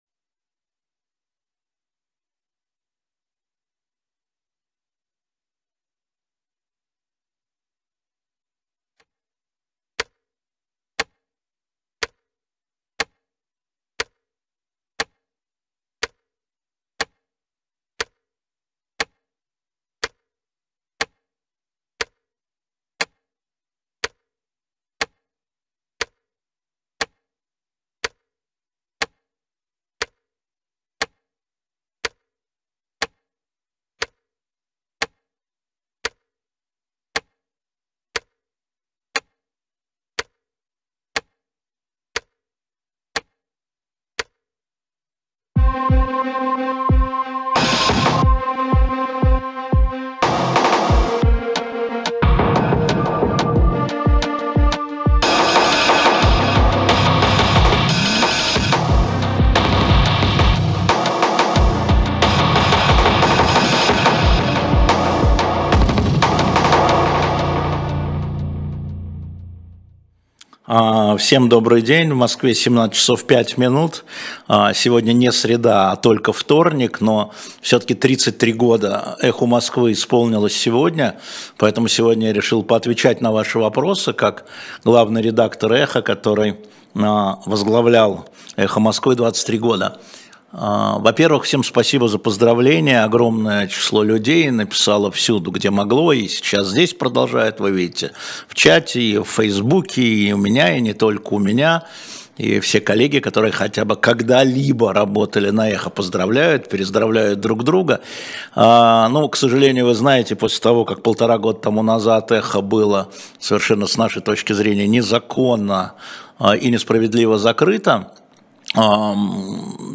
На ваши вопросы в прямом эфире отвечает Алексей Венедиктов